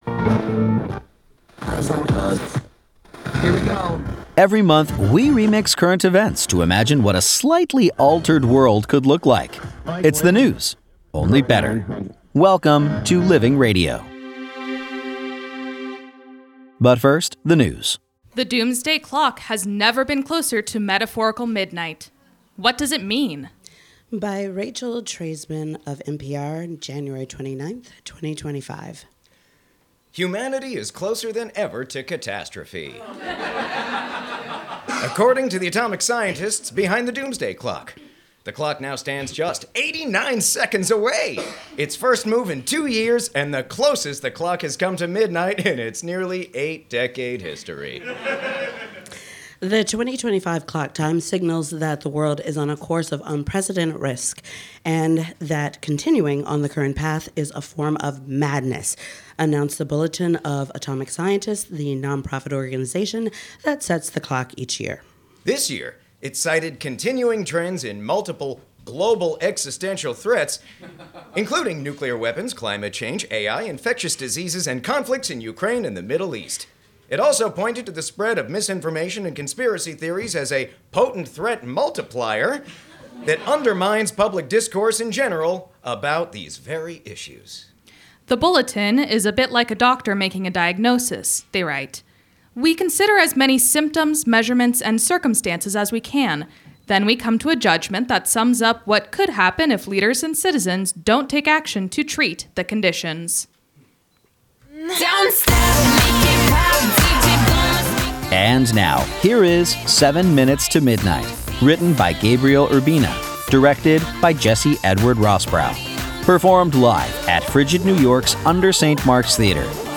performed live, February 3, 2025, at FRIGID New York’s UNDER St. Mark’s Theater